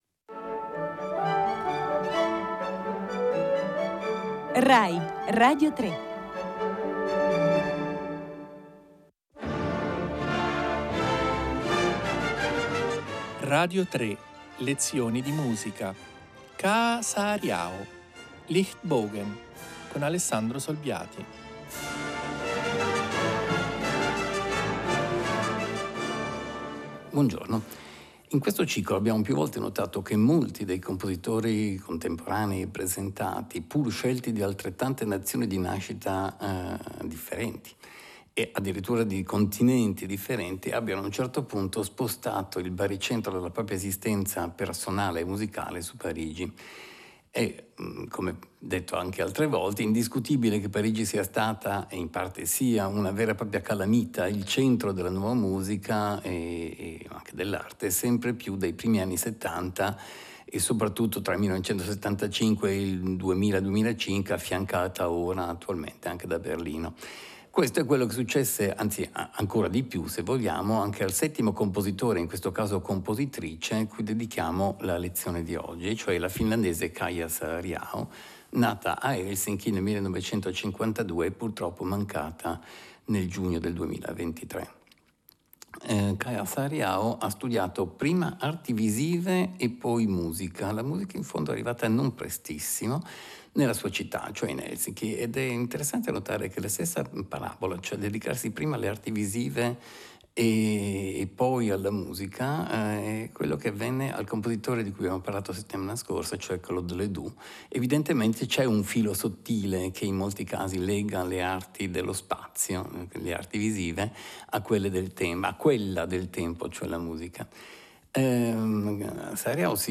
Musicologi e musicisti italiani tra i più affermati e riconosciuti, insieme alle voci storiche di alcuni conduttori delle principali trasmissioni musicali di Radio3, accompagnano gli ascoltatori in un percorso divulgativo che vuole essere a un tempo didattico e coinvolgente.
Grandi capolavori del repertorio sinfonico e di quello da camera, ma anche generi e forme musicali diversi, vengono esplorati con la proposta di esempi spesso eseguiti dal vivo al pianoforte o con l'offerta di ascolti rari e di grande qualità.